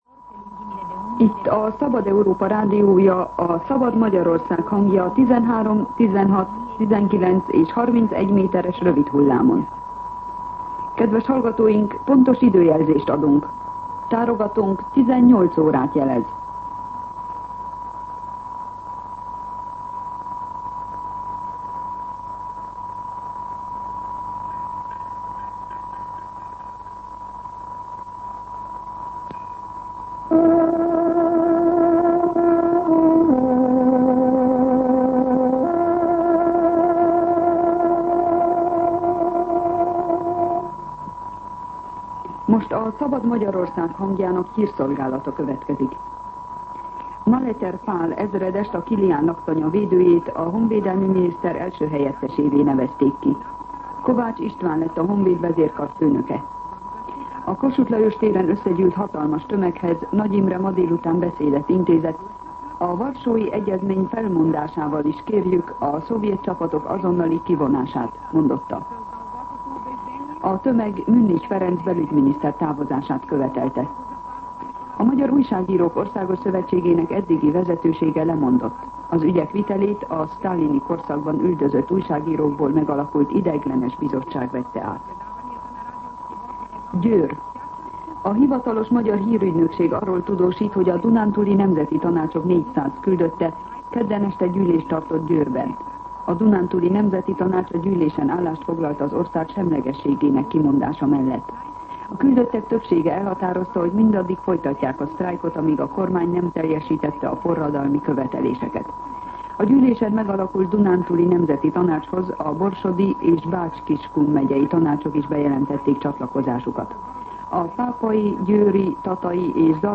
18:00 óra. Hírszolgálat